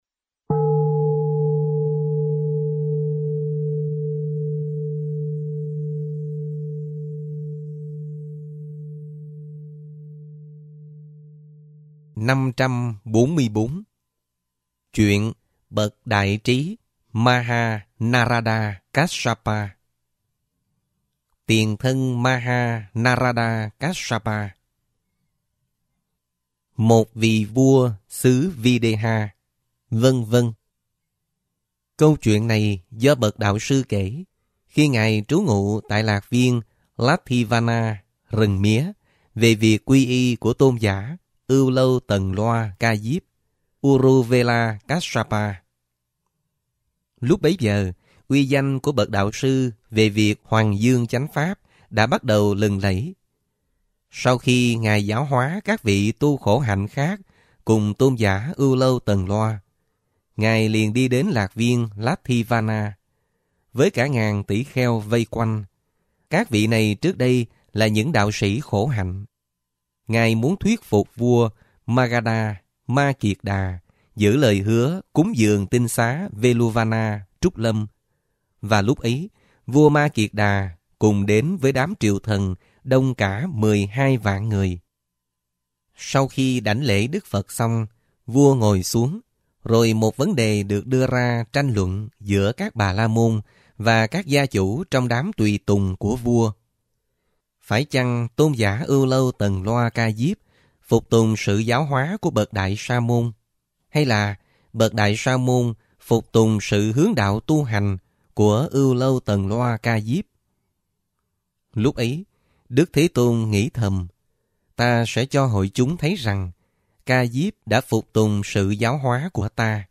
Kinh Tieu Bo 6 - Giong Mien Nam